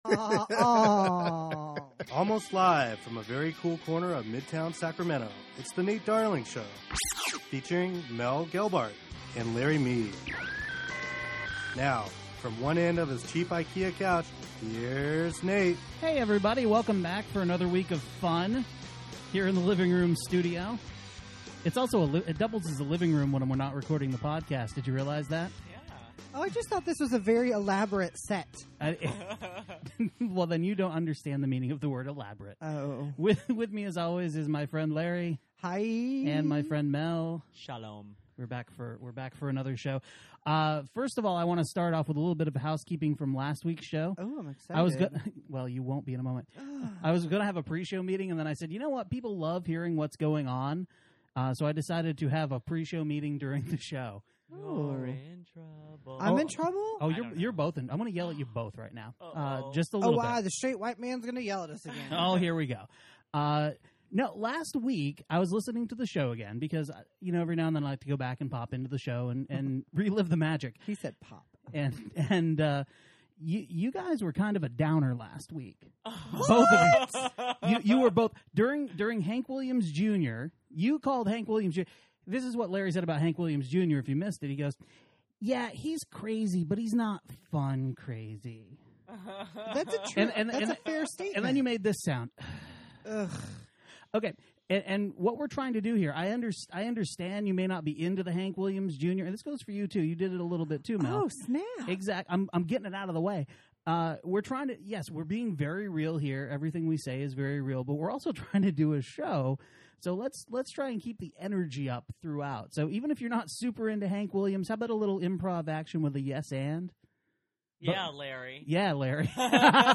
the living room studio